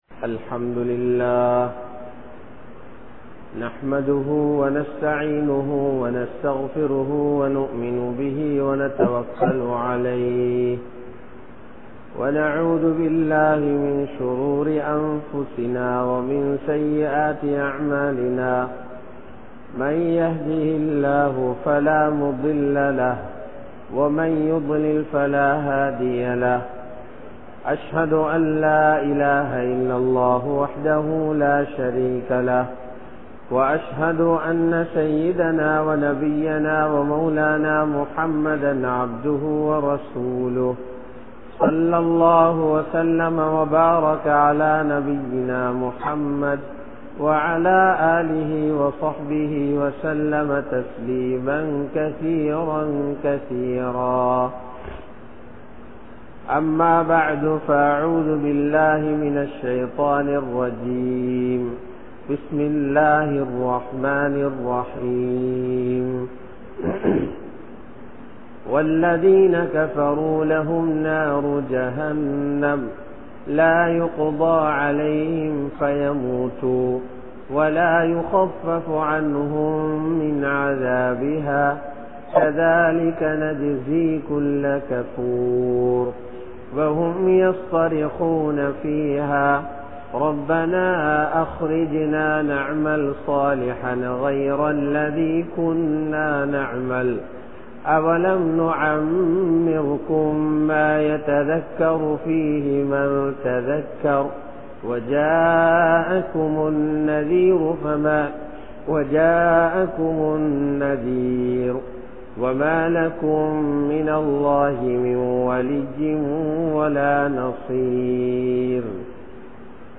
Koduramana Naraham (கொடூரமான நரகம்) | Audio Bayans | All Ceylon Muslim Youth Community | Addalaichenai
Kirinda Jumua Masjidh